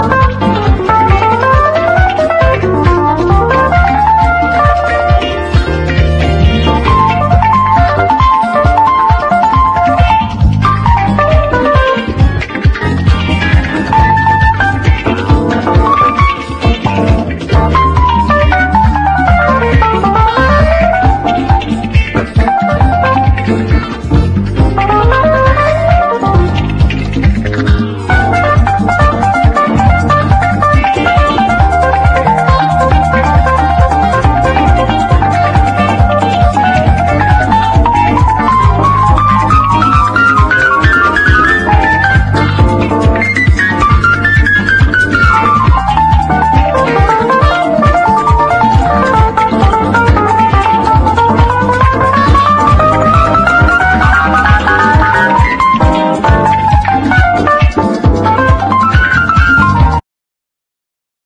EASY LISTENING / VOCAL / FRENCH / OLDIES